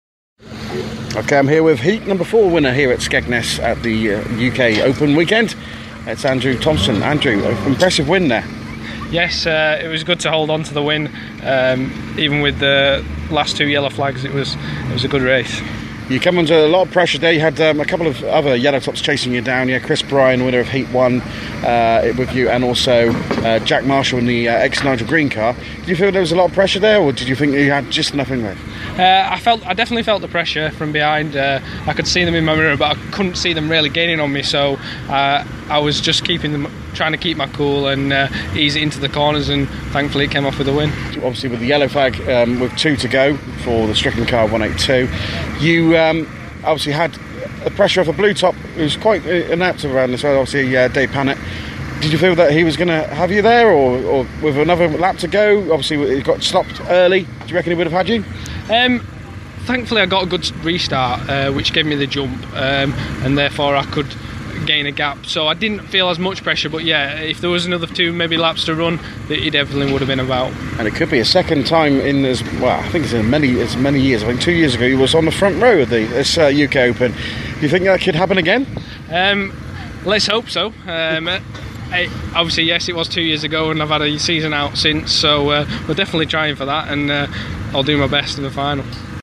Stox Cast - Interview